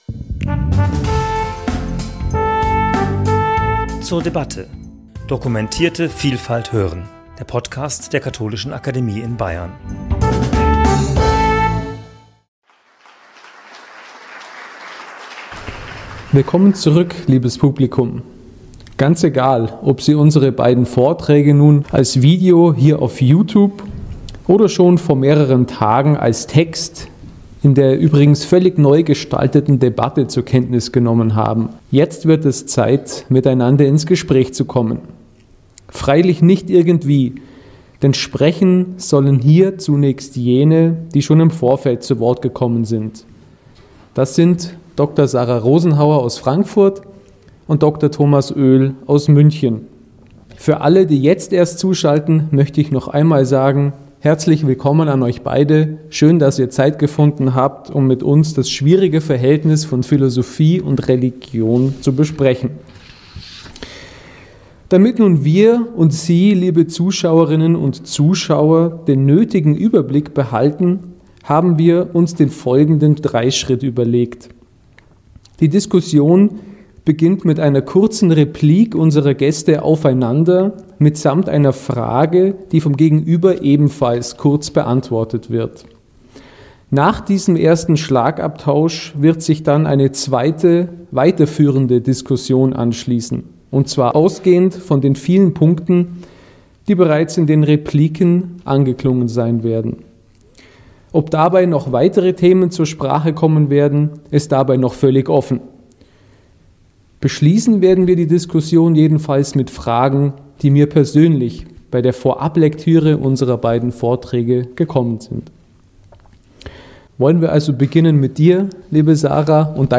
Das Gespräch zum Thema 'Vernunft und Offenbarung' fand am 15.2.2021 in der Katholischen Akademie in Bayern statt.